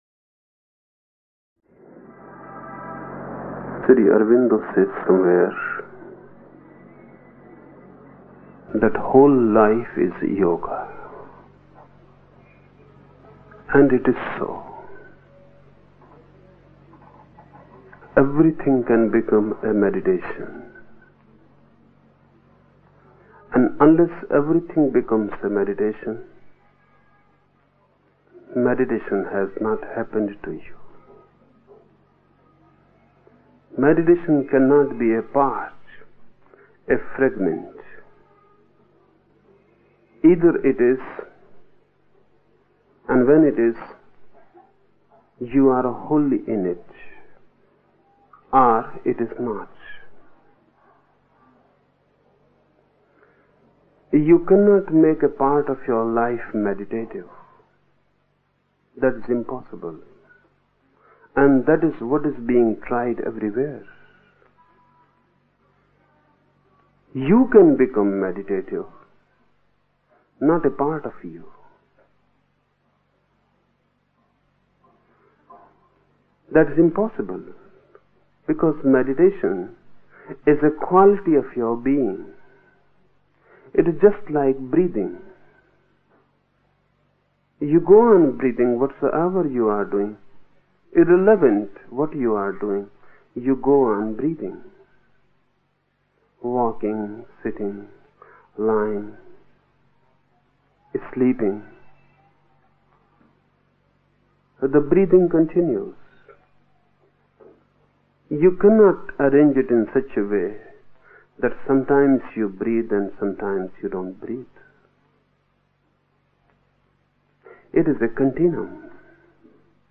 Each program has two parts, Listening Meditation (Osho discourse) and Satsang Meditation.
The Osho discourses in the listening meditations in this module are from the discourse series, The Book of Secrets in which Osho was speaking on Shiva’s 112 meditation techniques, and were given in Mumbai, India from 1972 to 1973.